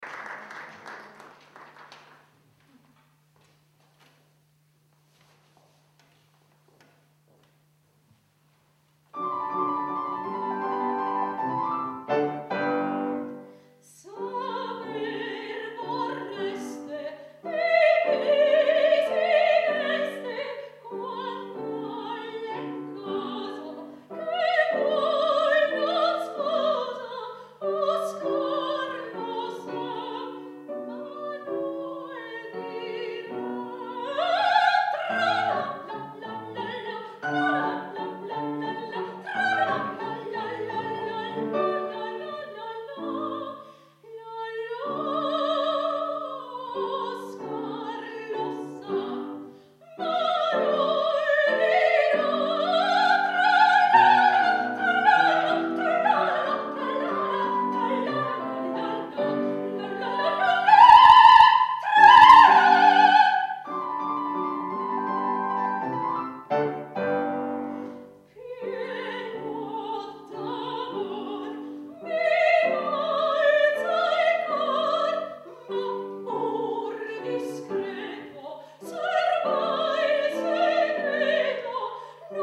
II OTTOBRE MUSICALE A PALAZZO VALPERGA, 17 ottobre 2009 - Concerto - Arie Italiane - G. VERDI da Un ballo in maschera - Saper vorreste